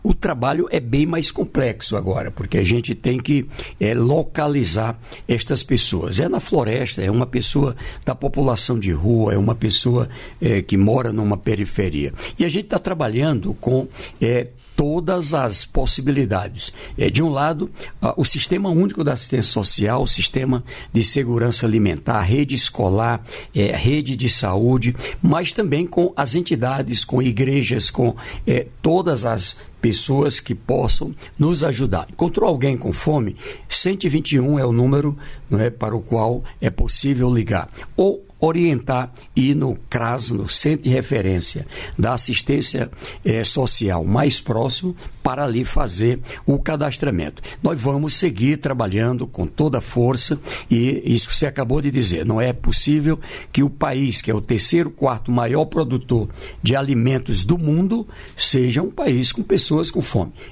Trecho da participação do ministro do Desenvolvimento e Assistência Social, Família e Combate à Fome, Wellington Dias, no programa "Bom Dia, Ministro" desta quinta-feira (7), nos estúdios da EBC em Brasília (DF).